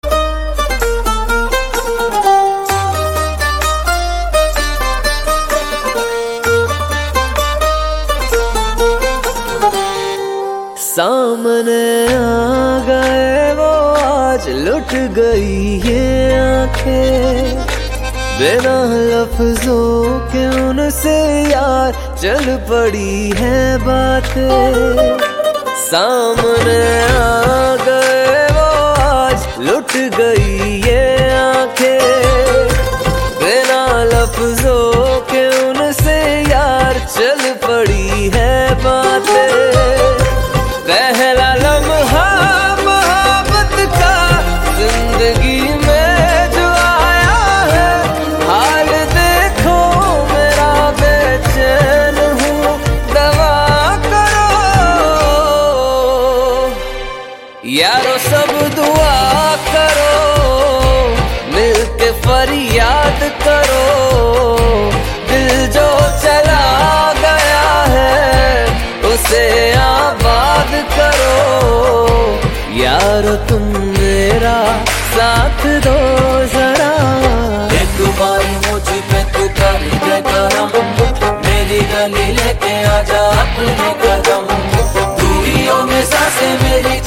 Categories Hindi ringtones